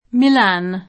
milä^n oggi, ma anche m&lën fino alla prima metà del ’900) — pn. diversa per Milan [ingl. m#ilän] cittadina dell’Indiana (S. U.)